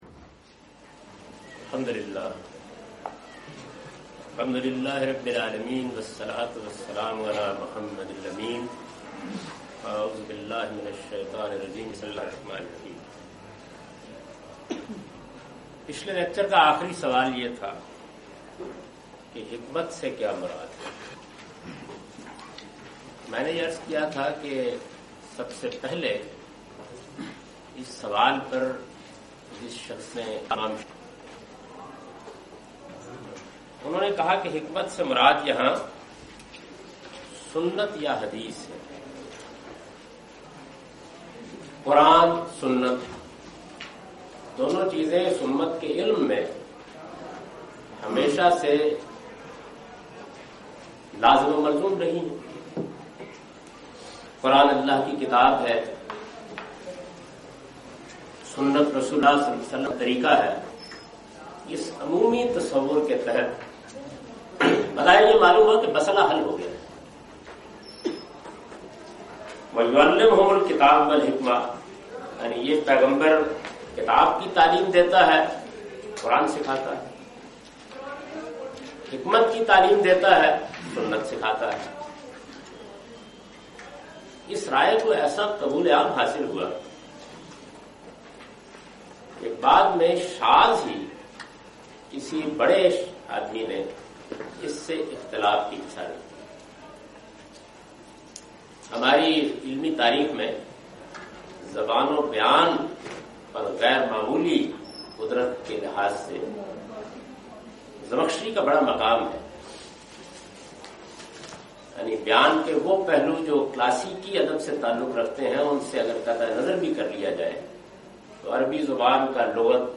In this lecture series, he not only presents his interpretation of these sources, but compares and contrasts his opinions with other major schools developed over the past 1400 years. This is an introductory lecture in which he shed some light on division of Al Kitab and Al Hikm'ah.